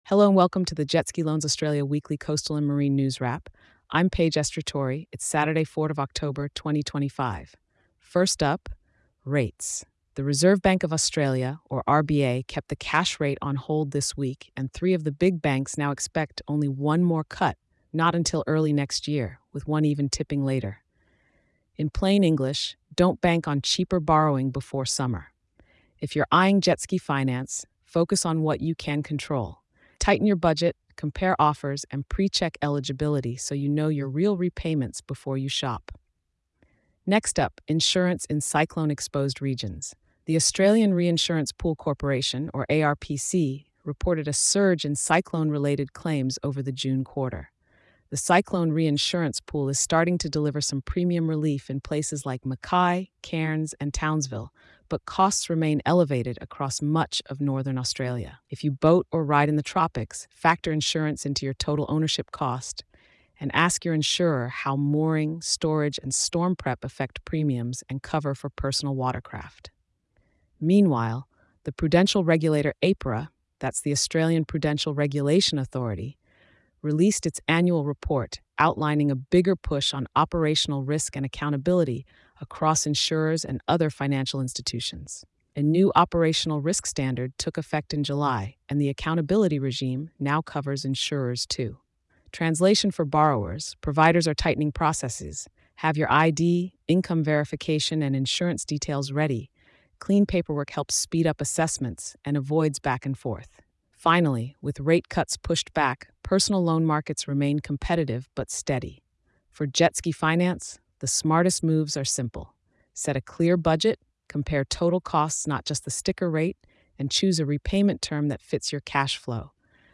Trust a calm, balanced summary to stay informed without the noise.